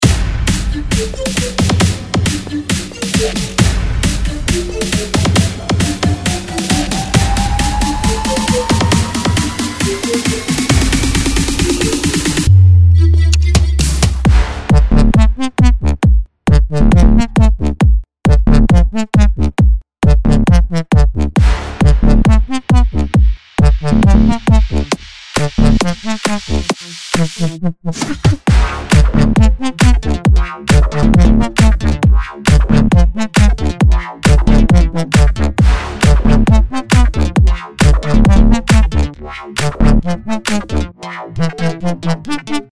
זה כל הקטע שזה יוצא מהקצב אבל במדיוק וזה מה שאני אוהב